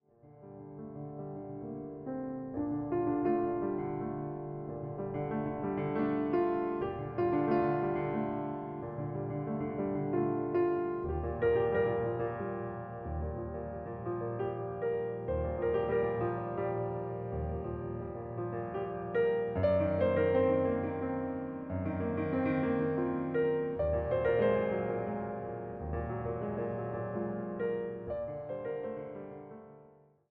様々な情景、抒情を見せるピアノ・ソロの世界が広がるアルバムとなっています。